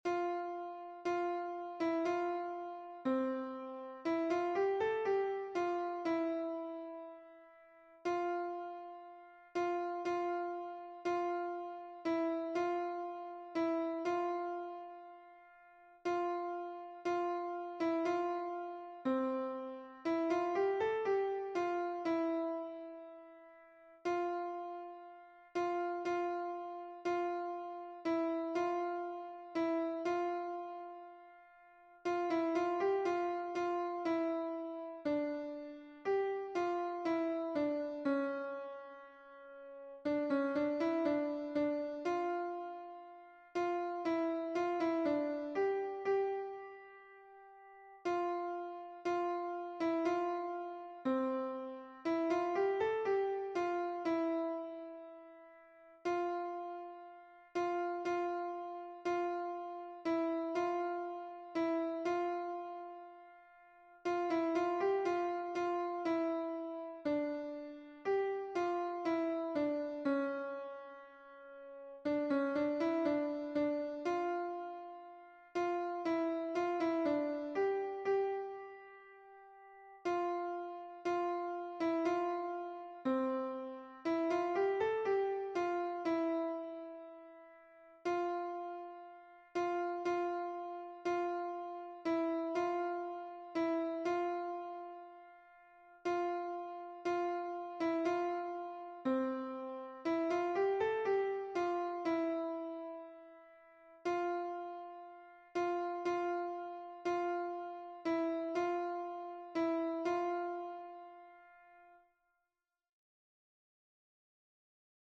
- Œuvre pour chœur à 4 voix mixtes (SATB)
Alto